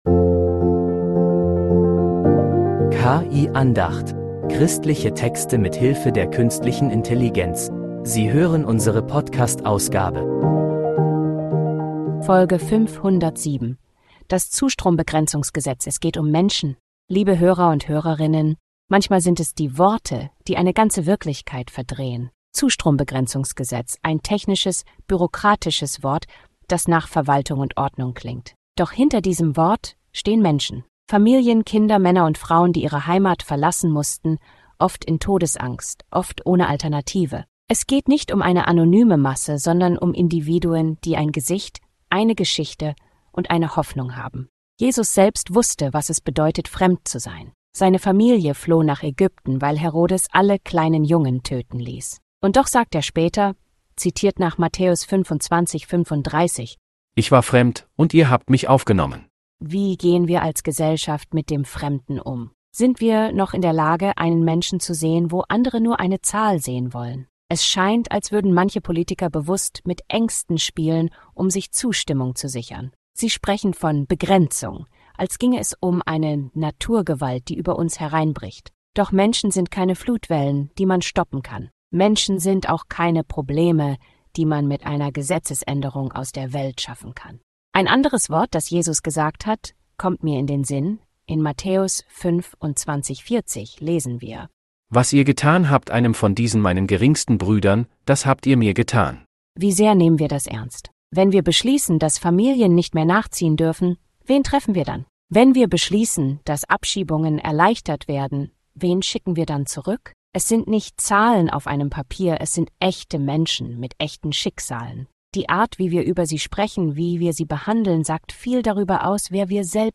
Christliche Texte mit Hilfe der Künstlichen Intelligenz